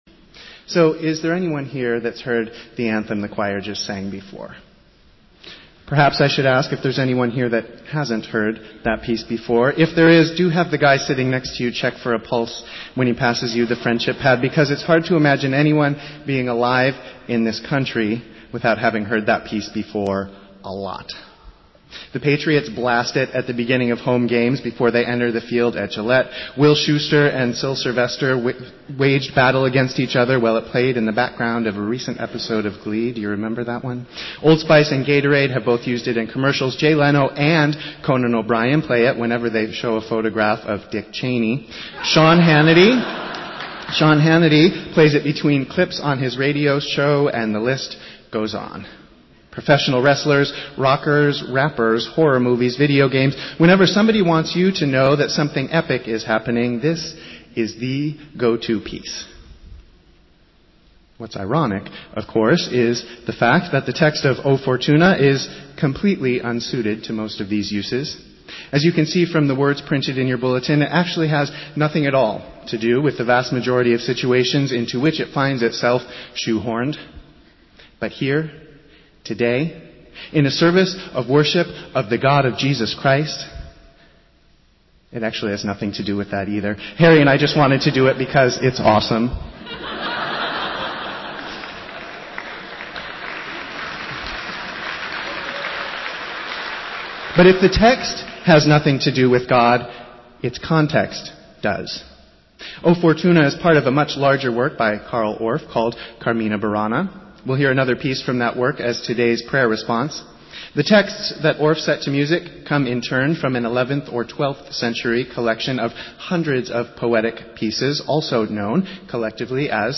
Festival Worship - Transfiguration Sunday